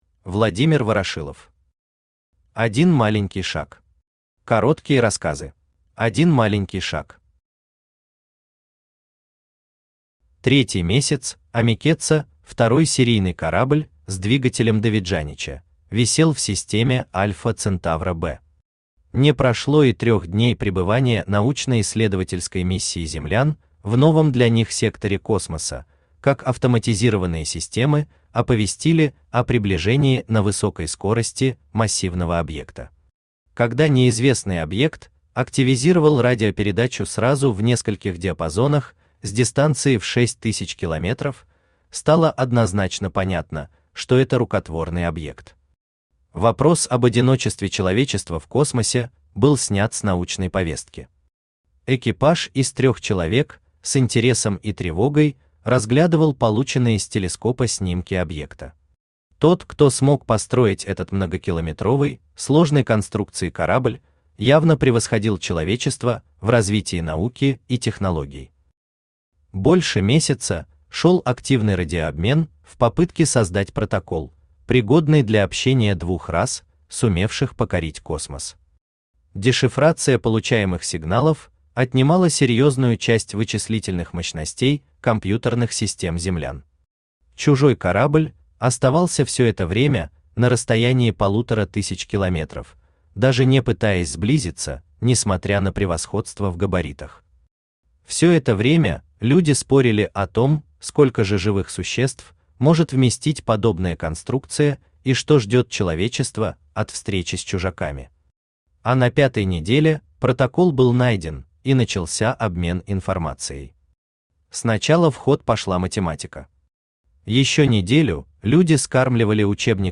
Aудиокнига Один маленький шаг. Короткие рассказы Автор Владимир Ворошилов Читает аудиокнигу Авточтец ЛитРес.